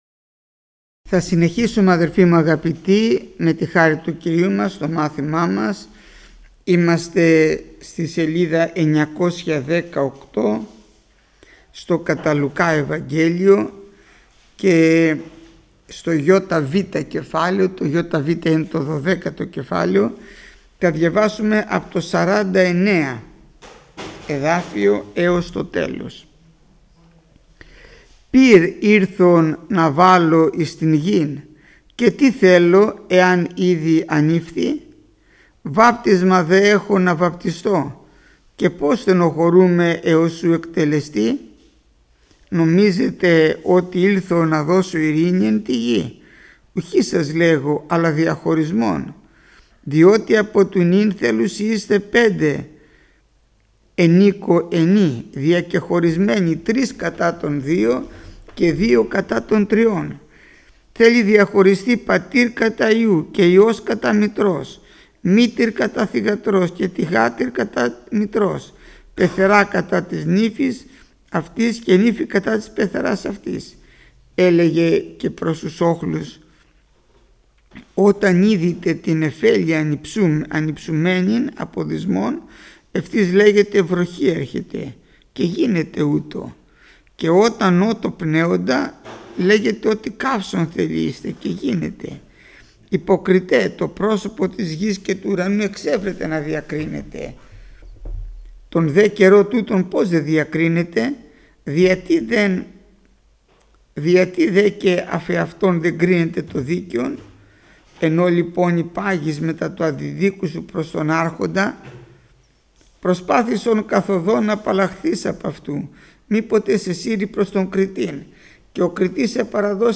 Μάθημα 482ο Γεννηθήτω το θέλημά σου